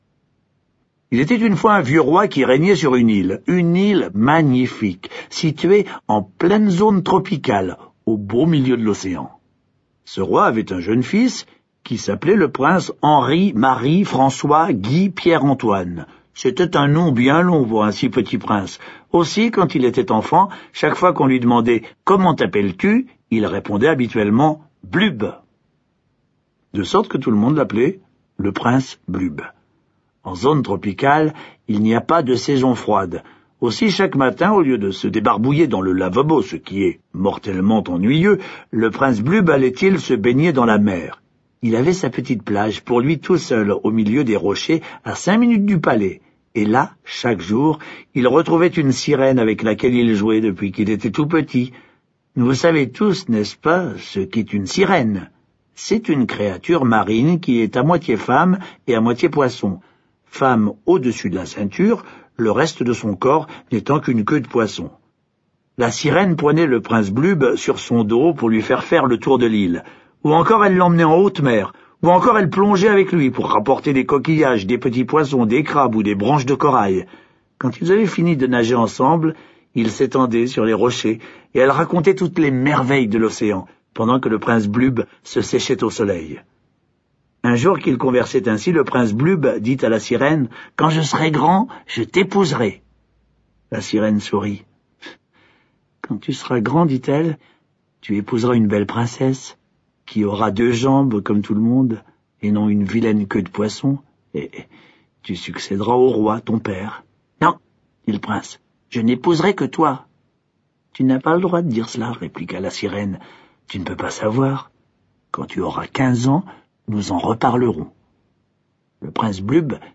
Genre : Livre Audio.